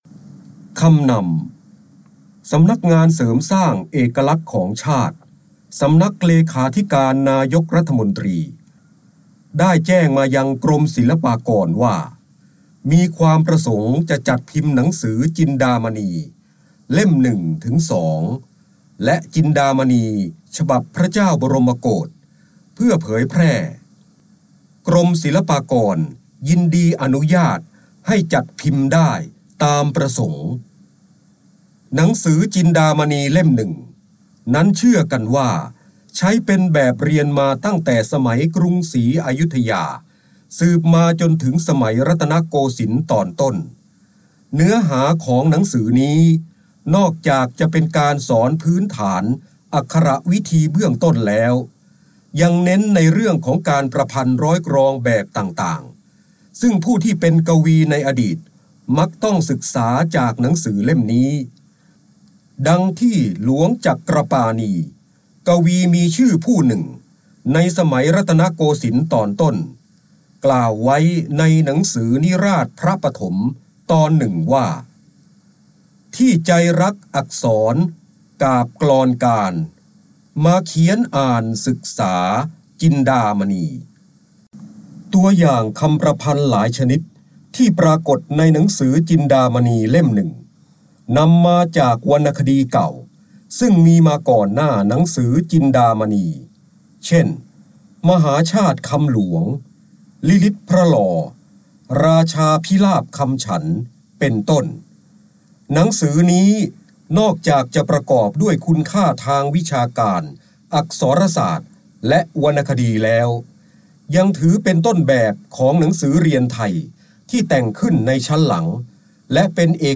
เสียงบรรยายจากหนังสือ จินดามณี คำนำ 2
คำสำคัญ : พระเจ้าบรมโกศ, การอ่านออกเสียง, จินดามณี, พระโหราธิบดี, ร้อยกรอง, ร้อยแก้ว
ลักษณะของสื่อ :   คลิปการเรียนรู้, คลิปเสียง